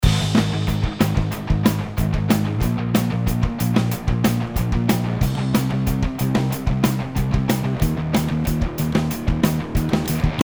このトラックのベースをちょっと歪ませて存在感を出していきたいと思います。
今回説明した方法のみを使って音作りした結果がこんな感じです。
高域部分には何もせず、中域を歪ませ、低域はスタイルを変えてふくよかな感じにしてみました。
音量はそのままにベースの存在感が少し上がってきていますよね。